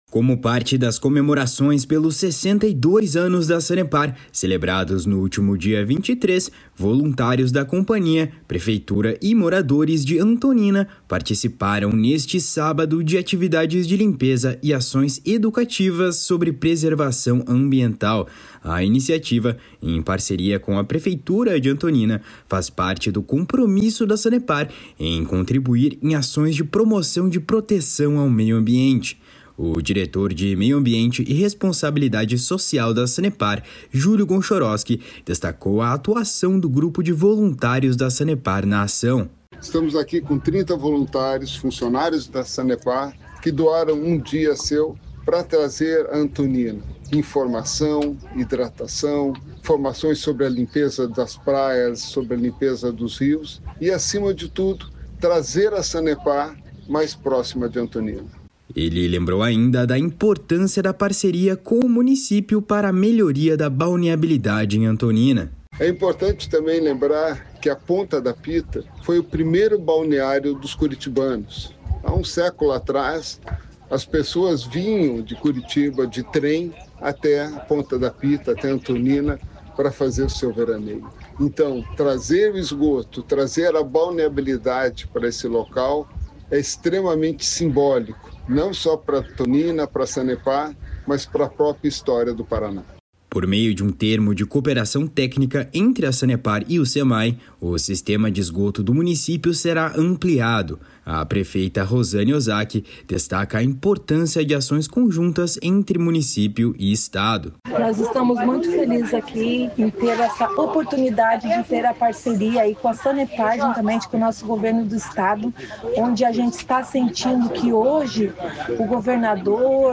A prefeita Rosane Osaki destaca a importância de ações conjuntas entre município e estado. // SONORA ROSANE OSAKI //